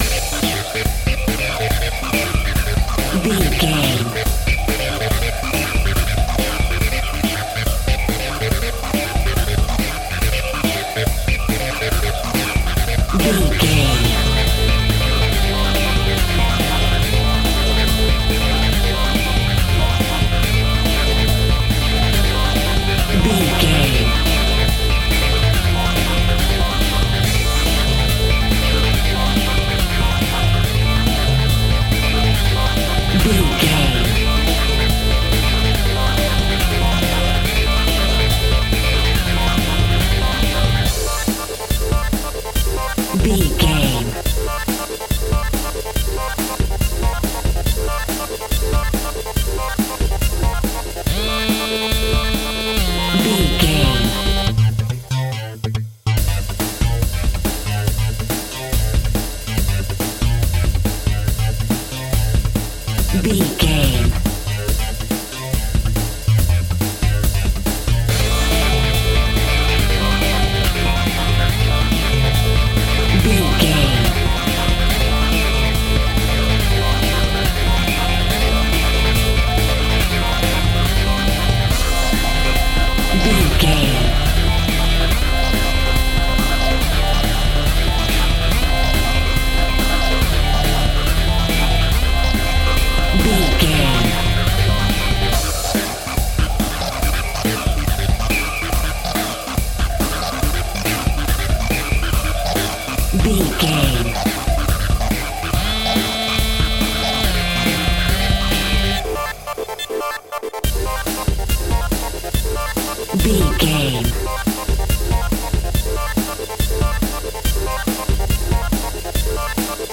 Powerful Breakbeat.
Epic / Action
Fast paced
Ionian/Major
futuristic
driving
energetic
drums
synthesiser
drum machine
power rock
electronic
synth lead
synth bass